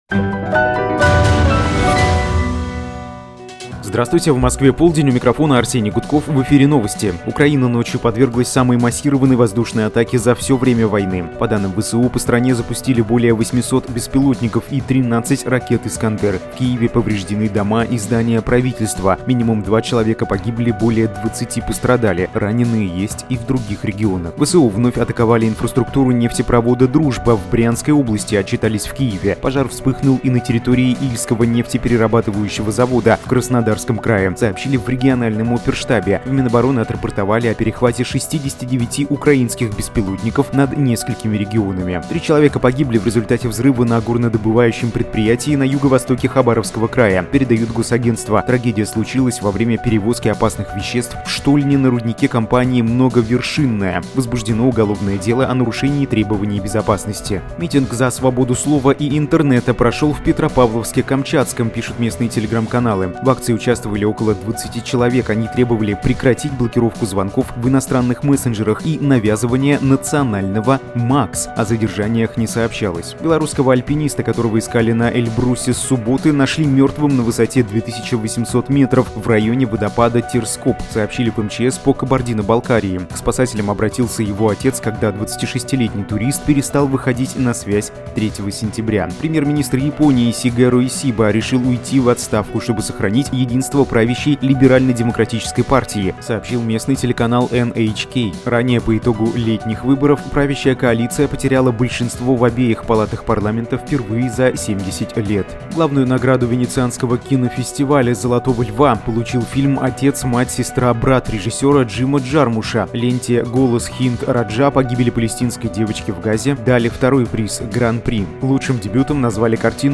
Слушайте свежий выпуск новостей «Эха»
Новости 12:00